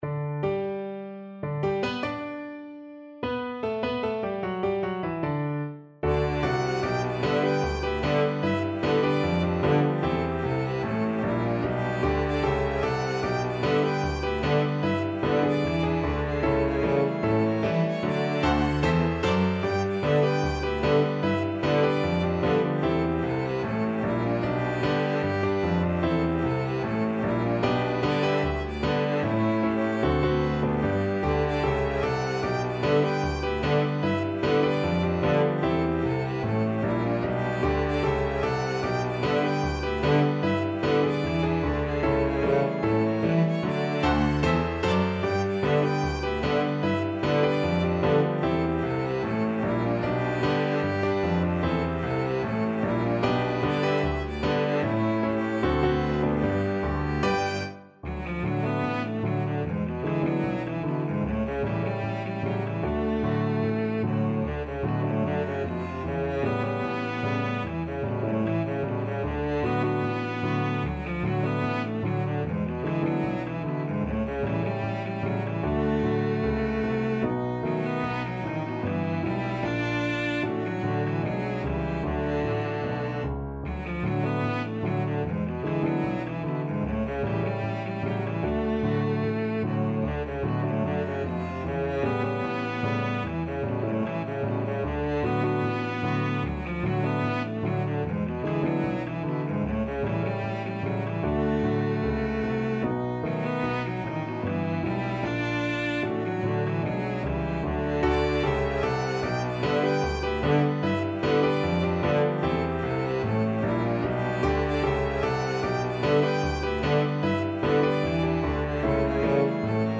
ViolinCelloPiano
= 75 Not fast INTRO.
2/4 (View more 2/4 Music)
Jazz (View more Jazz Piano Trio Music)